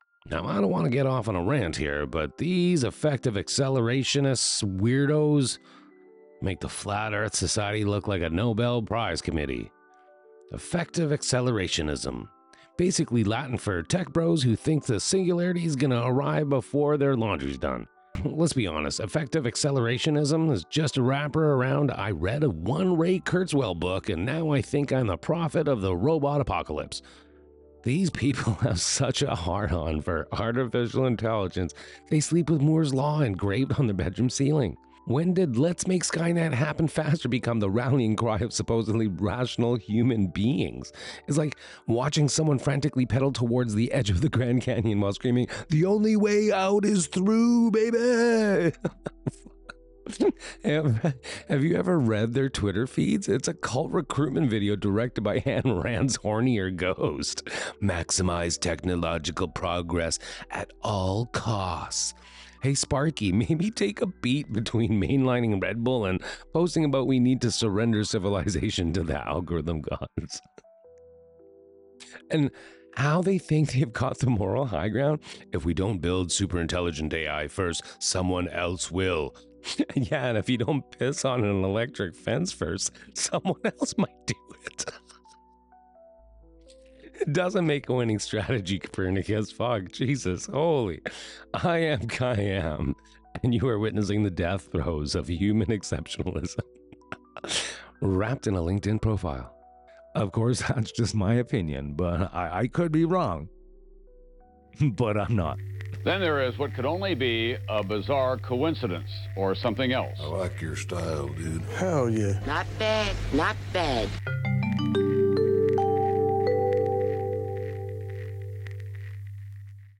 011-RANT.mp3